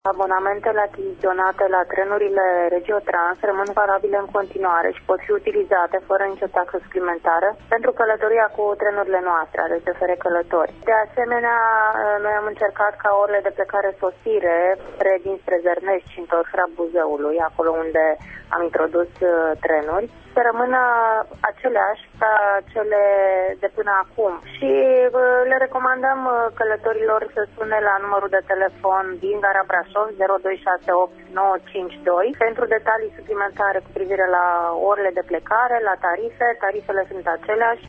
extras emisiunea „Pulsul Zilei”